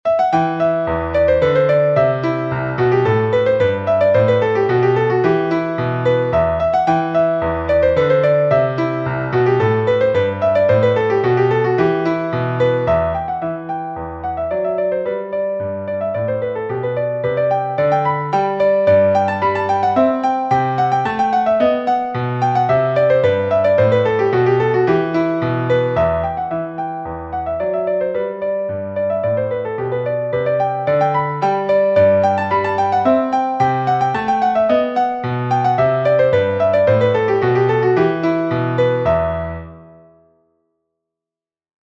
en Mi menor (E minor)
Música clásica